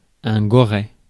Ääntäminen
Synonyymit cochon Ääntäminen France Tuntematon aksentti: IPA: /ɡɔ.ʁɛ/ Haettu sana löytyi näillä lähdekielillä: ranska Käännös Ääninäyte Substantiivit 1. piglet US Suku: m .